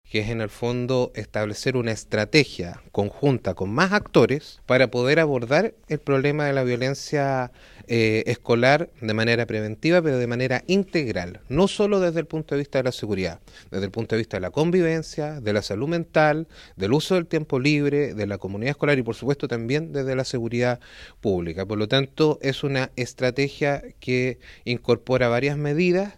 El seremi de Seguridad Pública de la región de La Araucanía, Israel Campuzano, dijo que se trazarán estrategias para abordar este fenómeno de manera preventiva e integral.